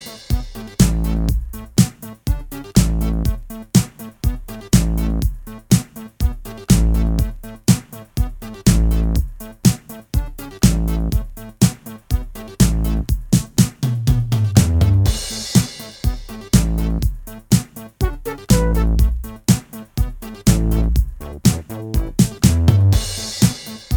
Minus All Guitars Reggae 3:53 Buy £1.50